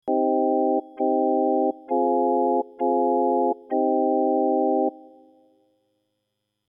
A short sequence of beatless minor chords: I-min, I-min, IV-min, IV-min, I-min
Audio1_tonal_space_I-min+IV-min.mp3